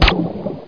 PSGWATER.mp3